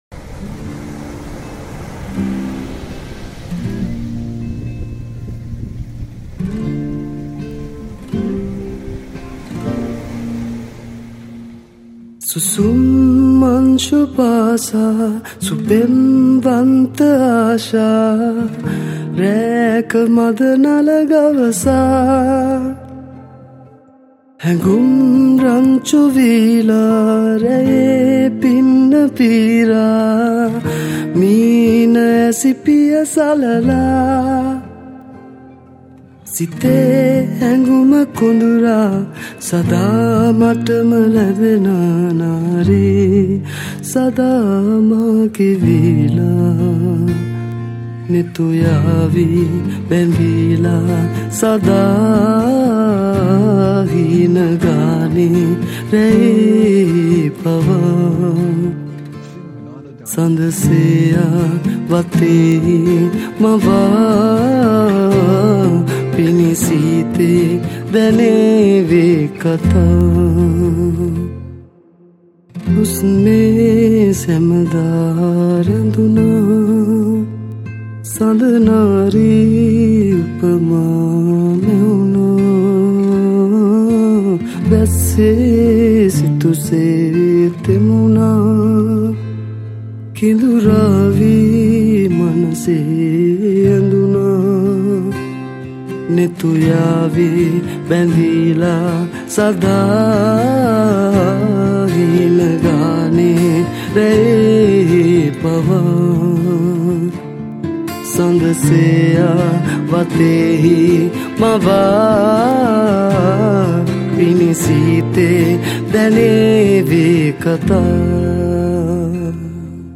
Acoustic Guitars / Electric Guitars / Banjo